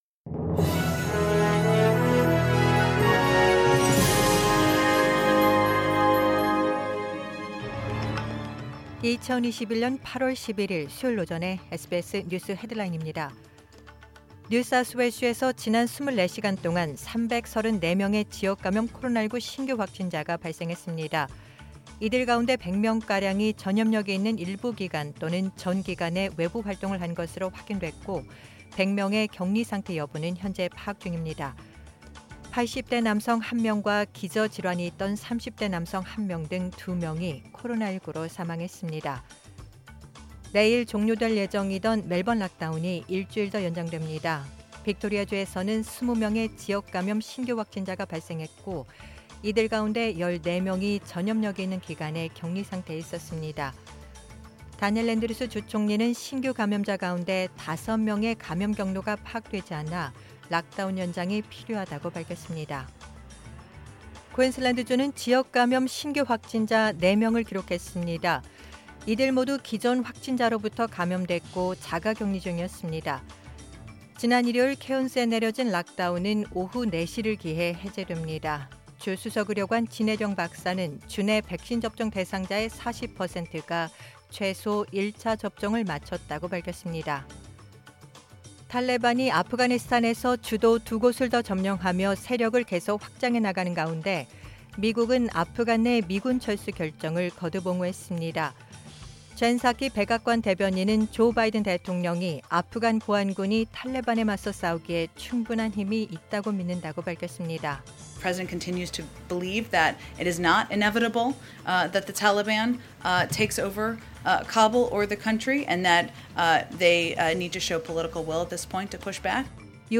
2021년 8월 11일 수요일 오전의 SBS 뉴스 헤드라인입니다.